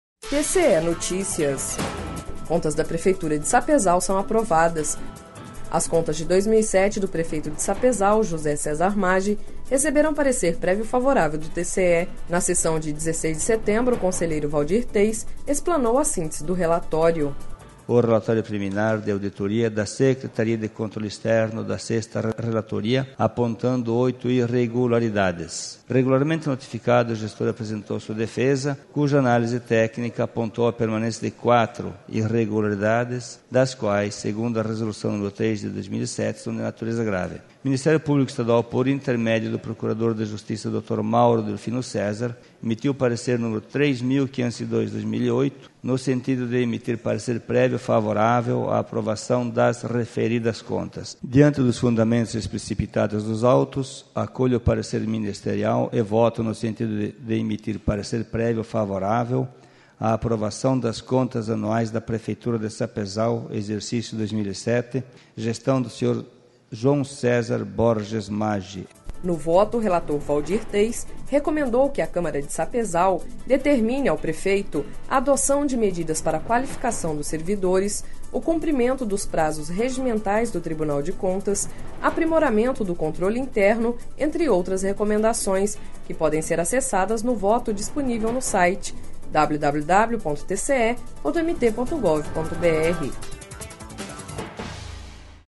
Sonora: Waldir Teis - conselheiro do TCE-MT